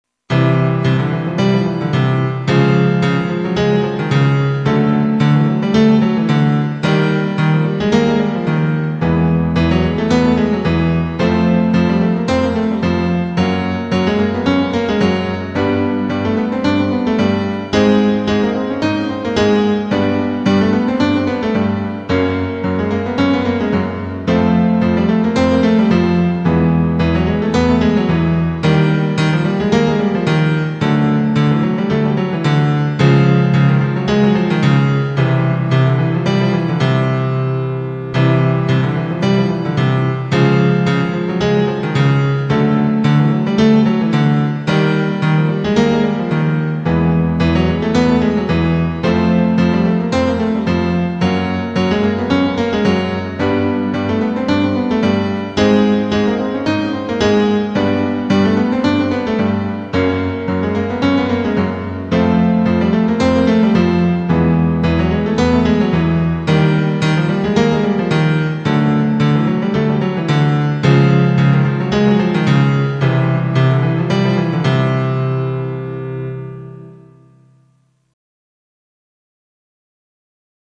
03-EXERCICIO-TRU-e-PRU_vibrando-a-lingua_em-volume-reduzido.mp3